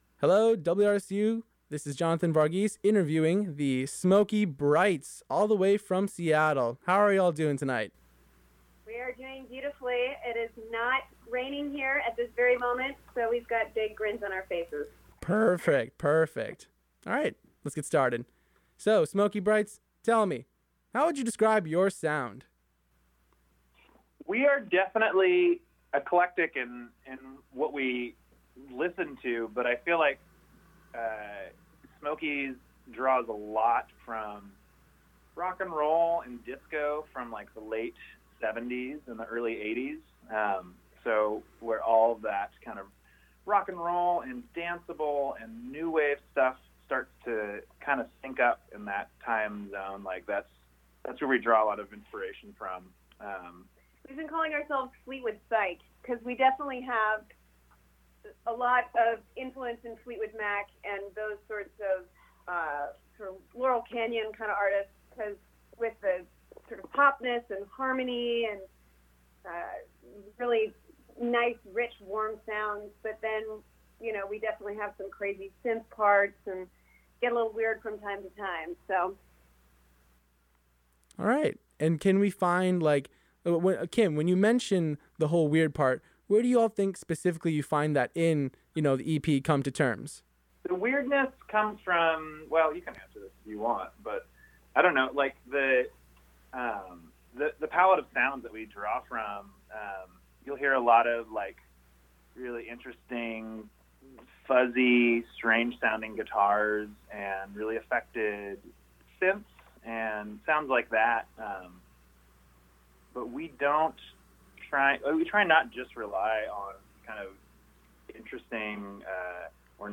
Smokey Brights Interview